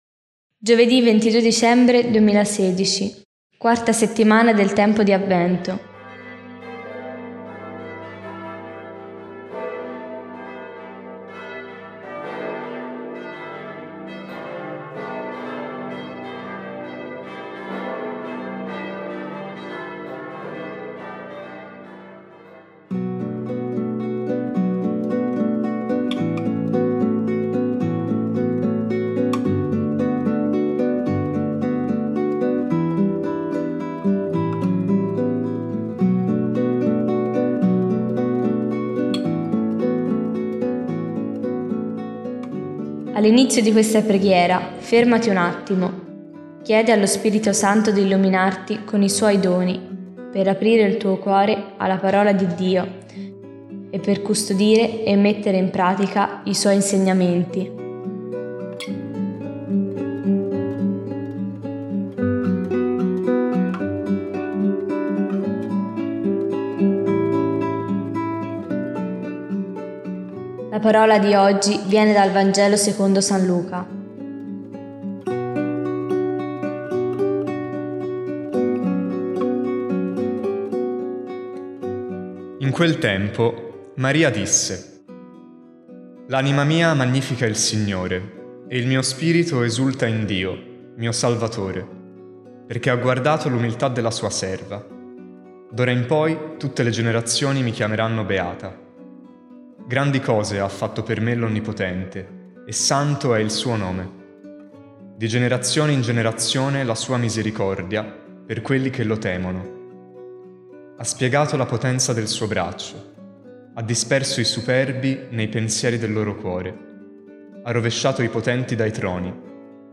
Escuela de guitarra clásica